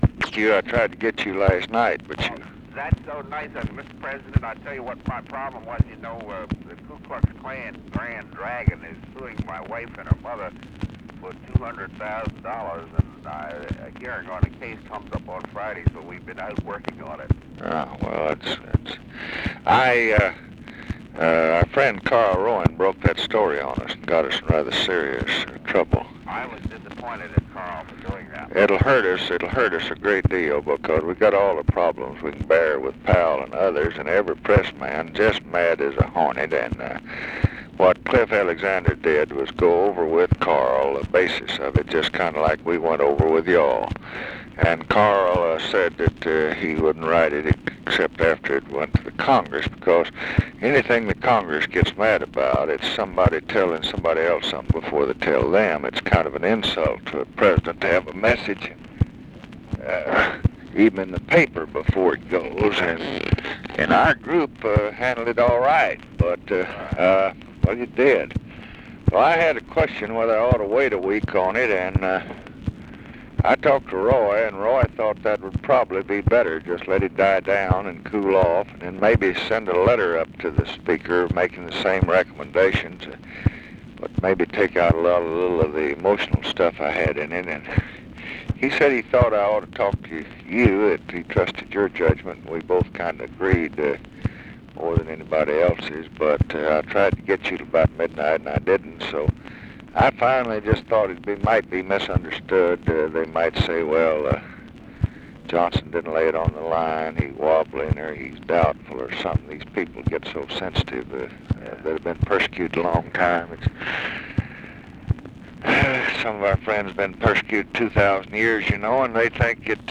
Conversation with CLARENCE MITCHELL, February 15, 1967
Secret White House Tapes